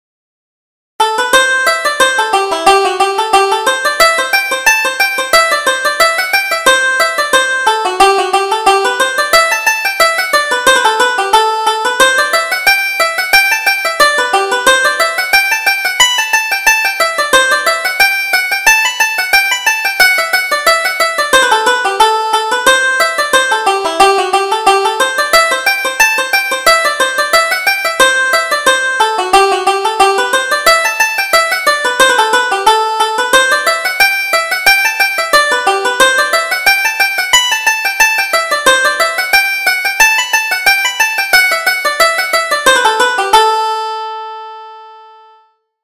Reel: The Chicago Reel